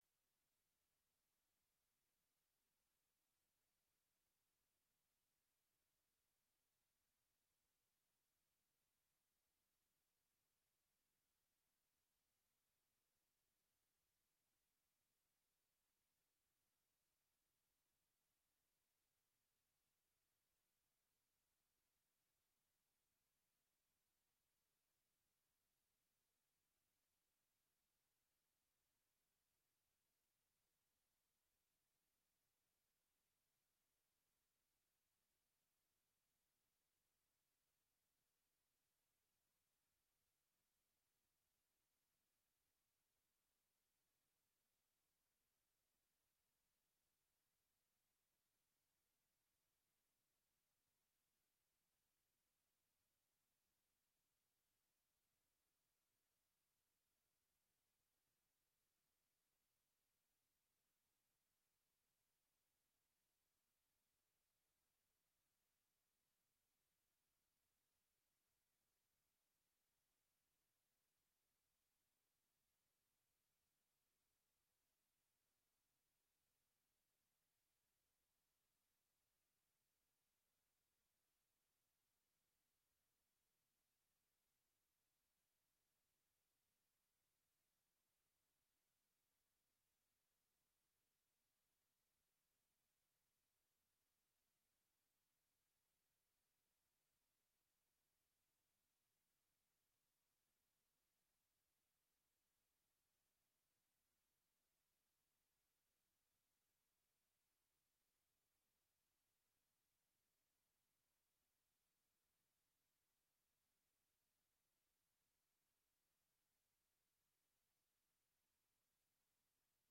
Rom: Store Eureka, 2/3 Eureka